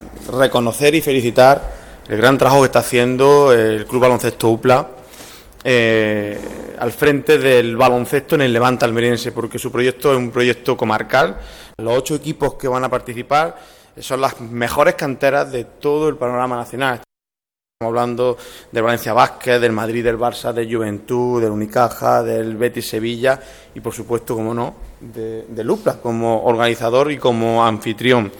han presentado esta nueva edición en el Pabellón Moisés Ruiz.